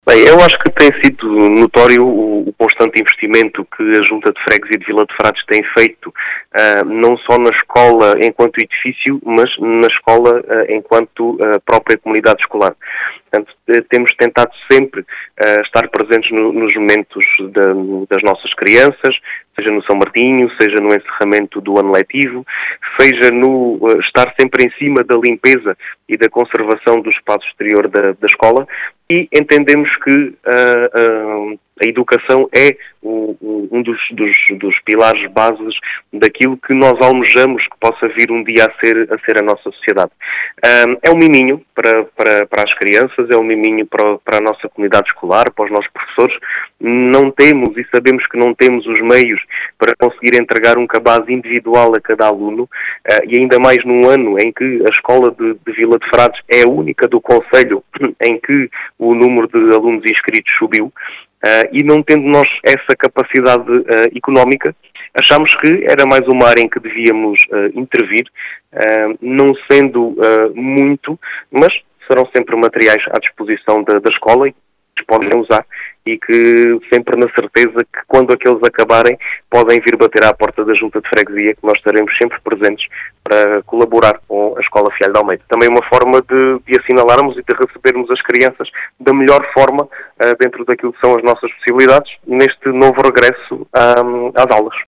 As explicações foram deixadas por Diogo Conqueiro, presidente da junta de freguesia de Vila de Frades que fala num “constante investimento” na escola Fialho de Almeida nas suas várias dimensões.